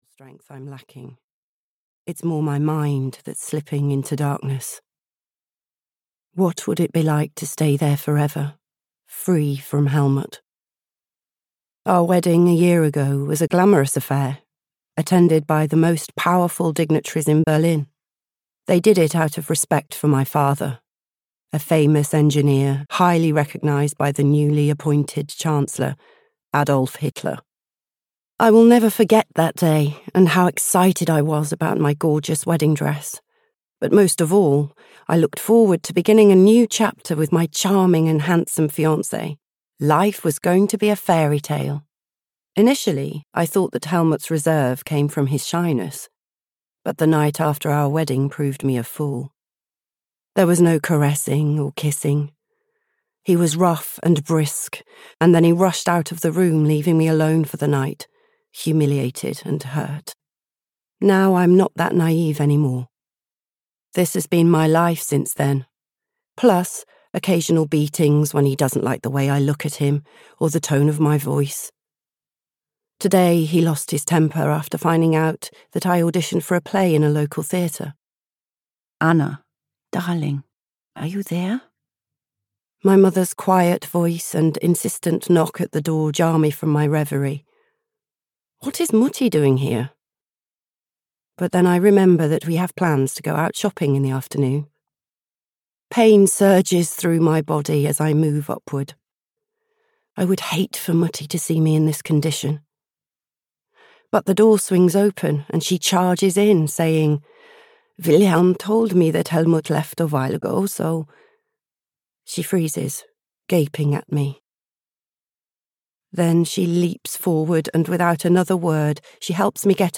The Resistance Wife (EN) audiokniha
Ukázka z knihy